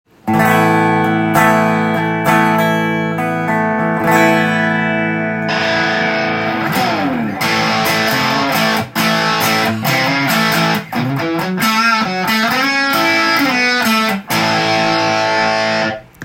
試しにクリーントーンからスイッチオンして弾いてみた
やはり値段が高いので部品で良くなったのでしょうか？昔のなんとも言えないBOSS
独特の音痩せ感がかなり減った印象です。
boss.destotion1.m4a